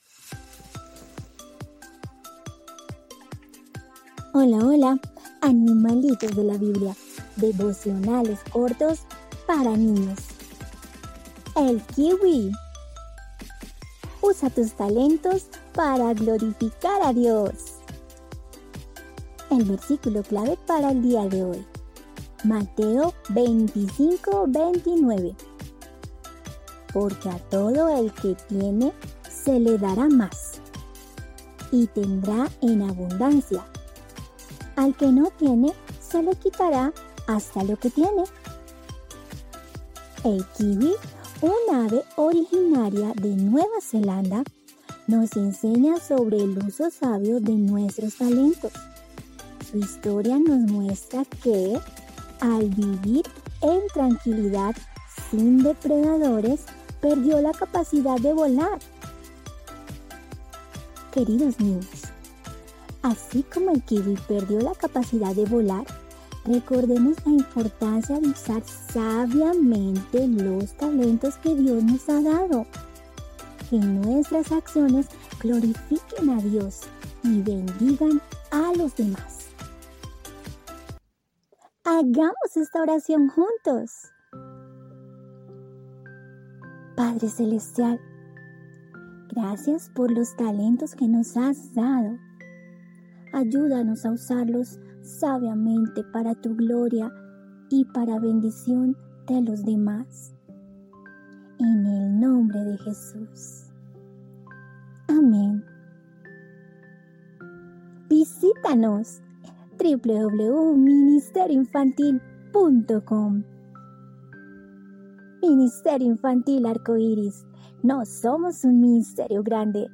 Animalitos de la Biblia – Devocionales Cortos para Niños